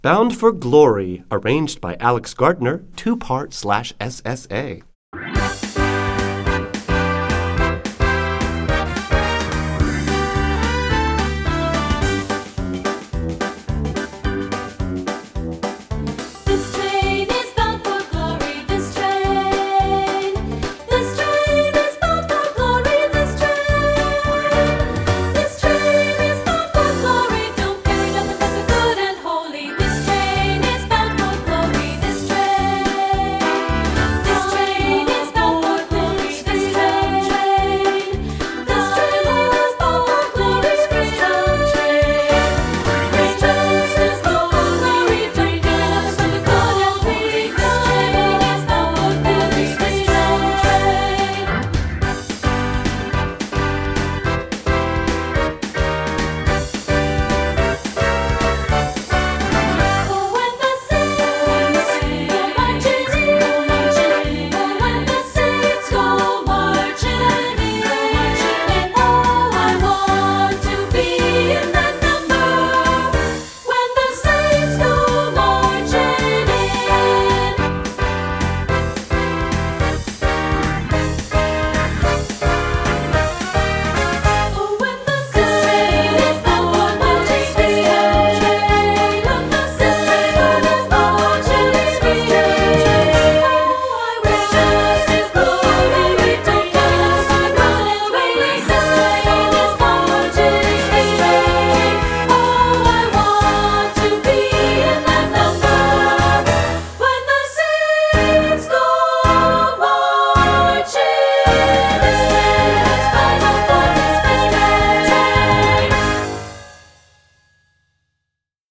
Voicing: 2-Part or SSA